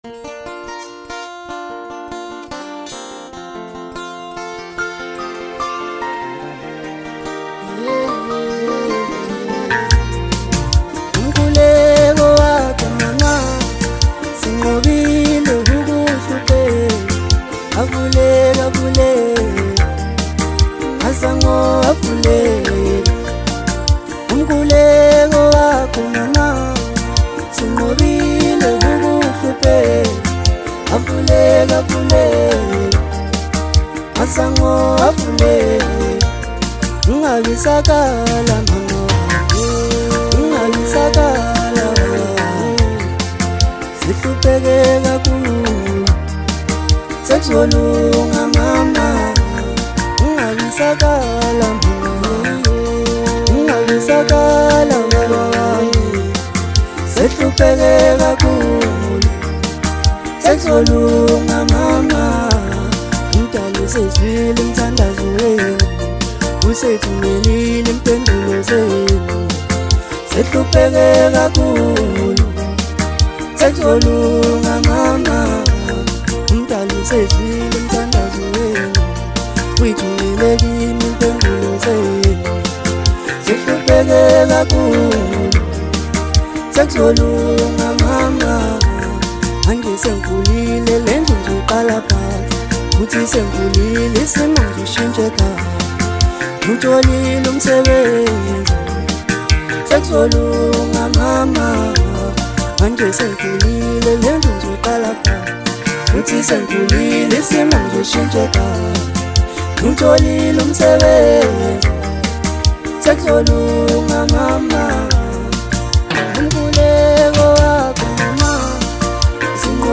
Amapiano
South African singer-songwriter